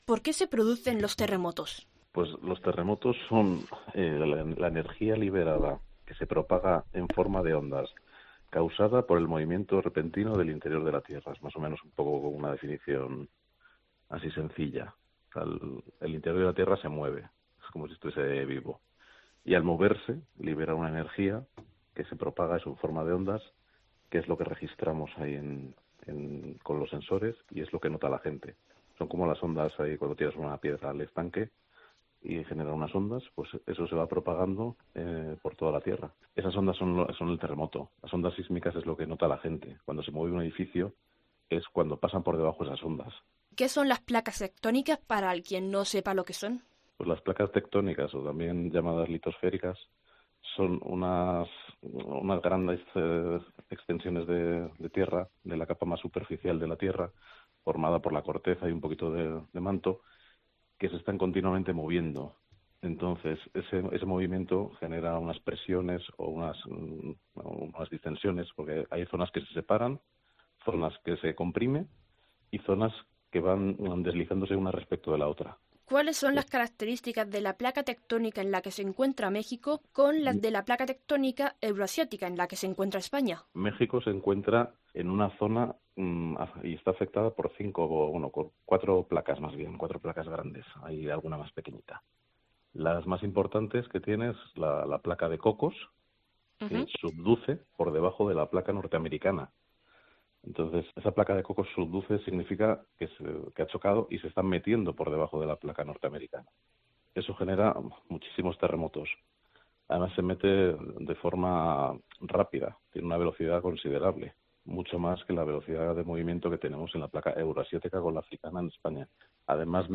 ¿Está España preparada para un terremoto como el que ha sacudido México? Un sismólogo nos responde